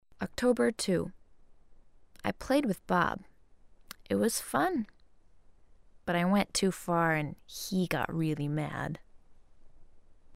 VOICE / CUTSCENES
Eileen Says most things 2 to 3 times over in a different tone of voice depending on how much damage has been done to her (shows how "possessed" she's getting).
SH4-Forest-Cutscene-Eileen-014.mp3